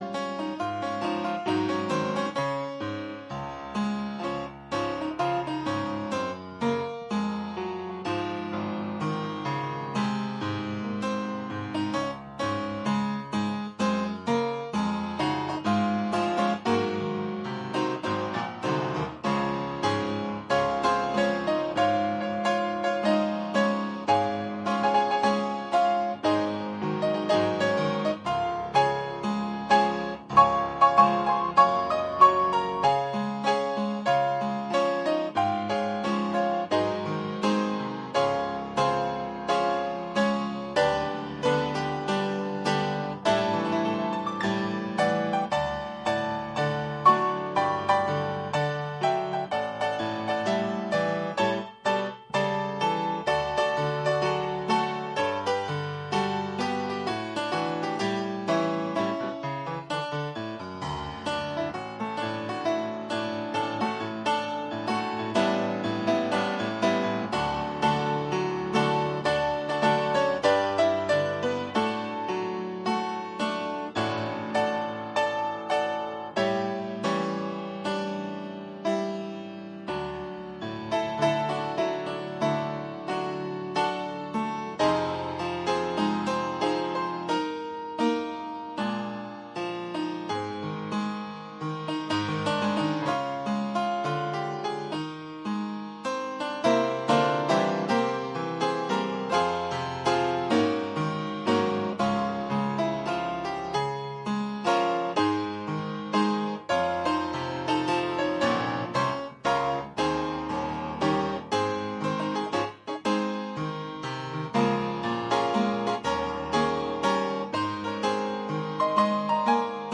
Mid Week Bible Study From John